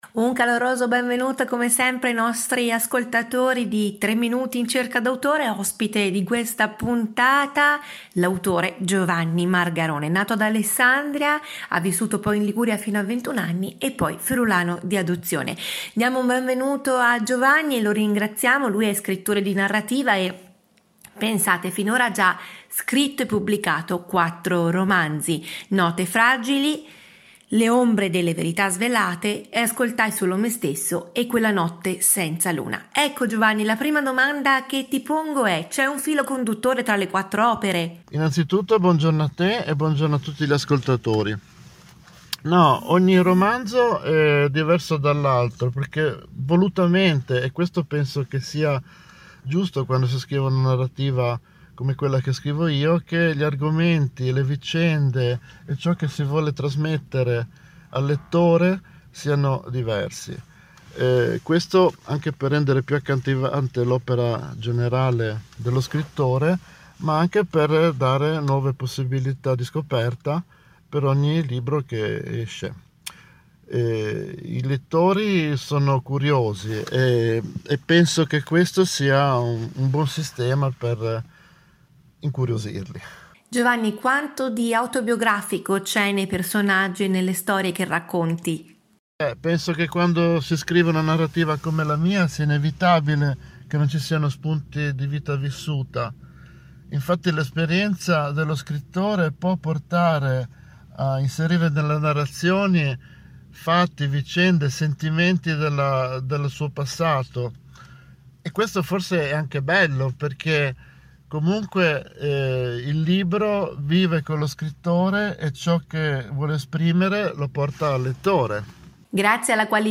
RASSEGNA STAMPA: ospite di Radio Varese Web, ecco l’intervista